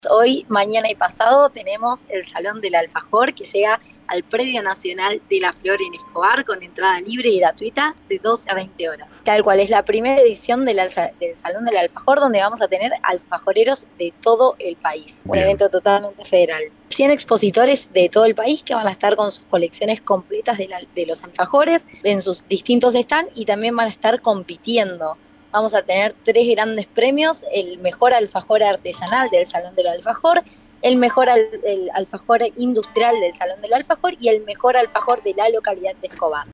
en una entrevista con FM Ilusiones.